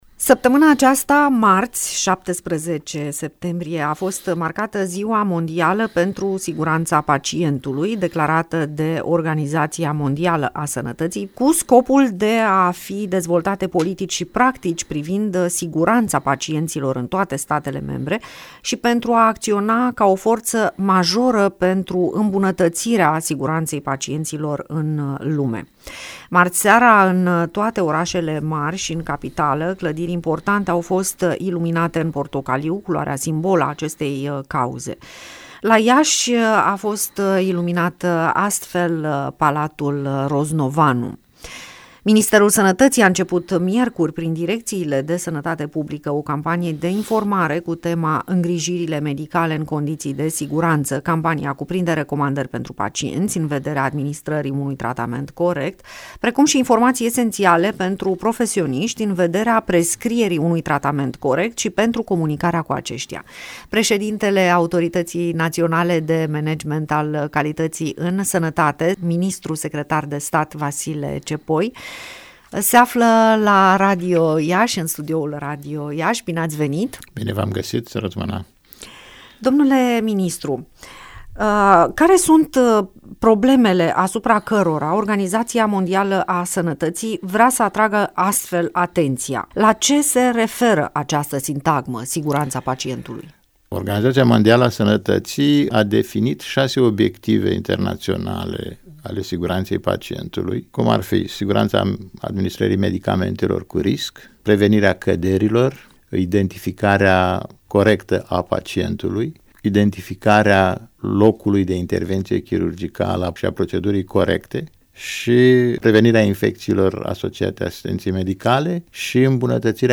Siguranţa pacientului, o preocupare la nivel mondial - INTERVIU cu ministrul secretar de stat, Vasile Cepoi - Radio Iaşi – Cel mai ascultat radio regional - știri, muzică și evenimente